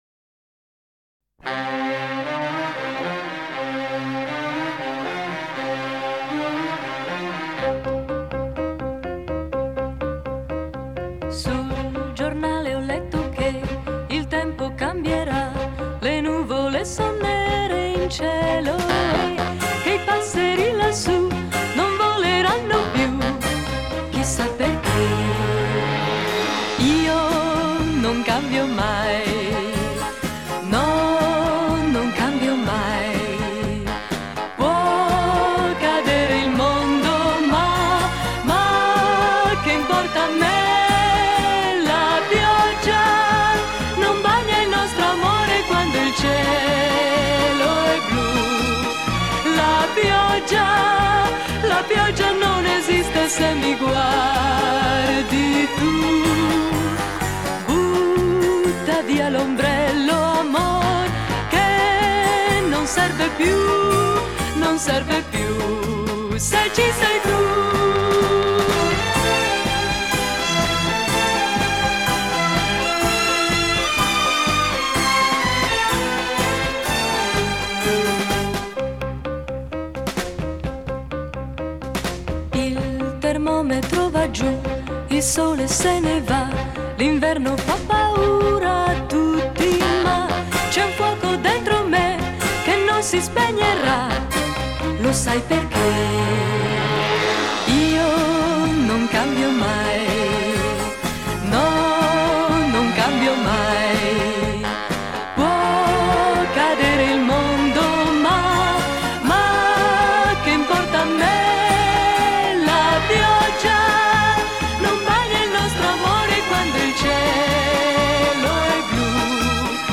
Genre: Retro Pop